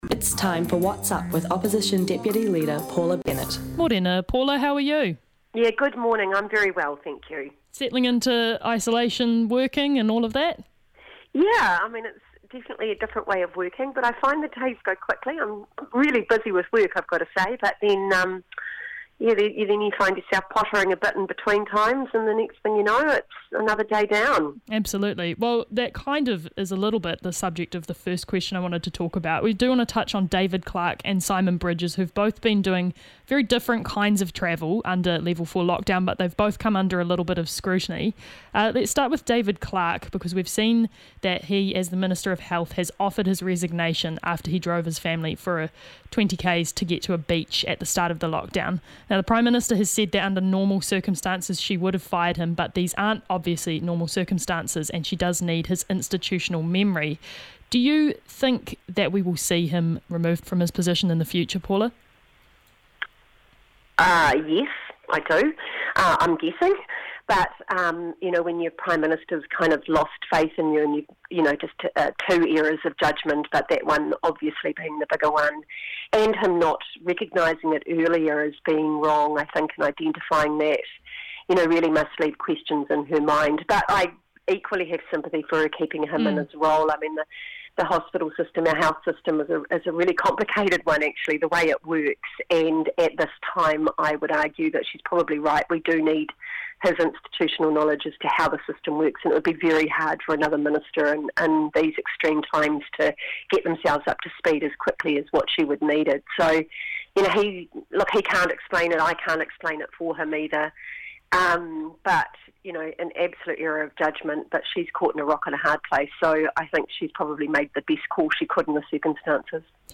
The pair discuss the movements of Health Minister David Clark and Leader of the Opposition Simon Bridges, how MP's are keeping safe, and what would be good to see as we move into the next phase.